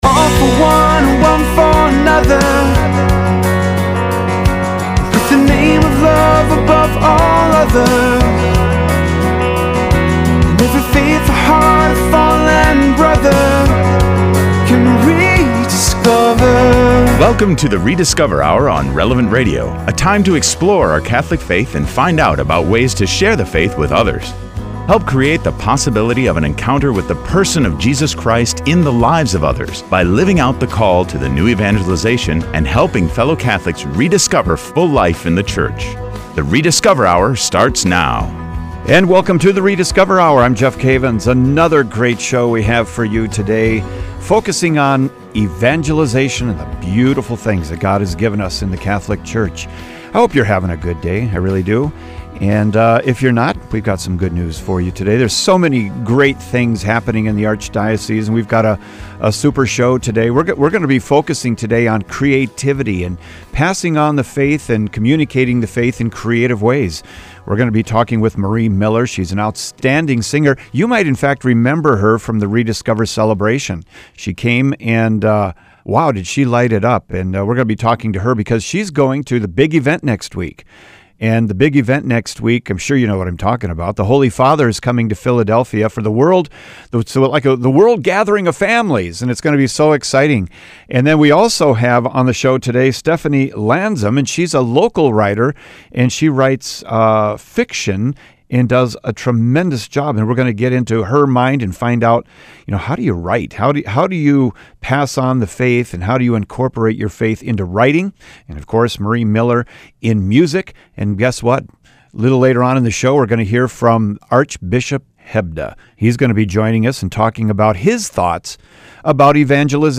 On The Rediscover: Hour we are speaking with three wonderful guests about “Creative Evangelization.”
We’re honored to have Archbishop Hebda on the hour as well, who speaks with us about his experience in evangelization.